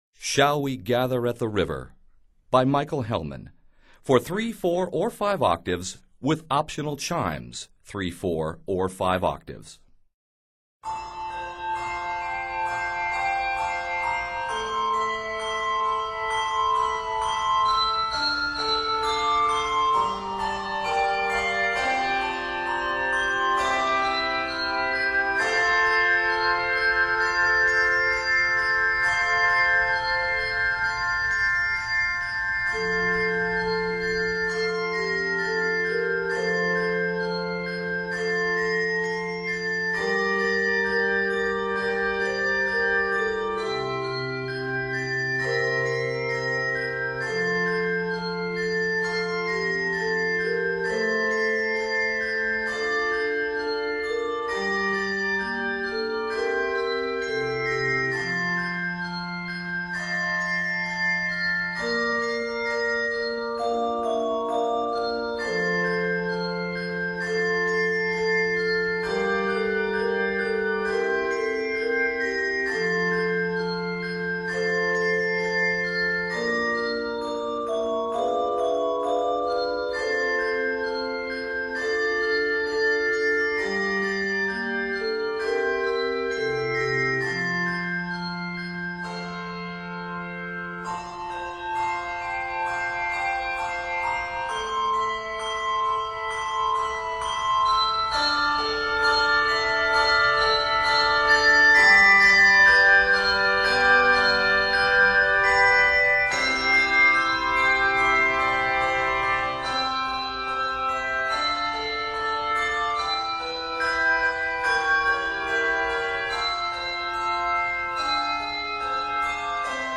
Set in F Major and C Major, this piece is 83 measures.